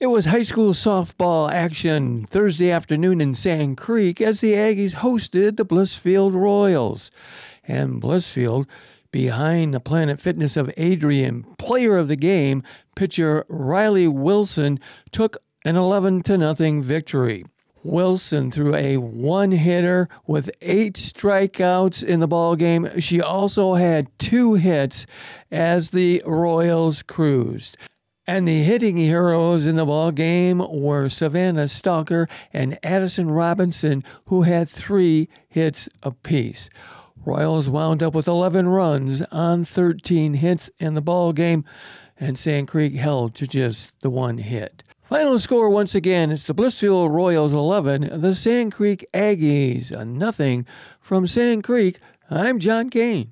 nws8521-aaa_sports_wrap.wav